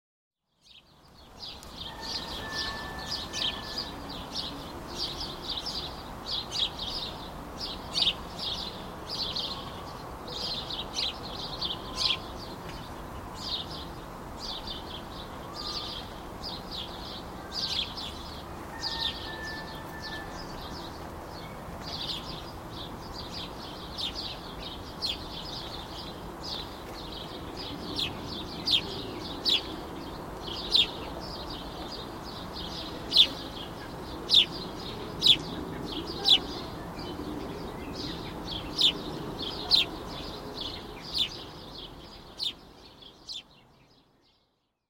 Sparrows